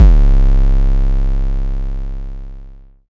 TH Krazy 808.wav